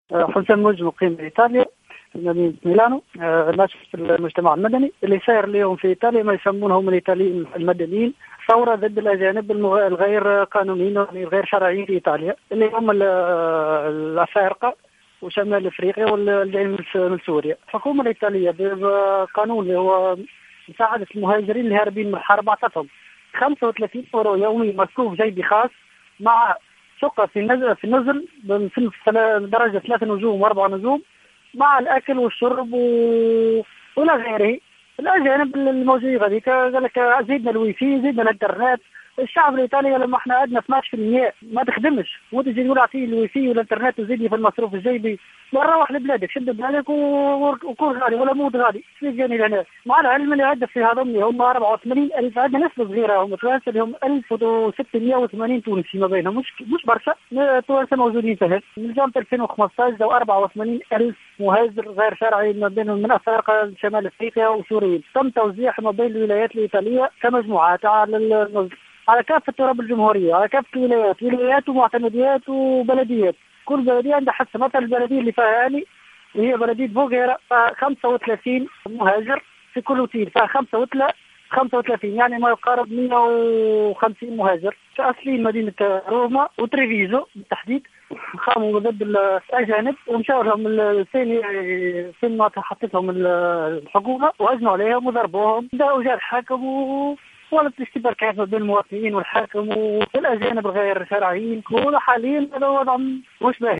تصريح لجوْهرة أف أم
مواطن تونسي مقيم بايطاليا وناشط في المجتمع المدني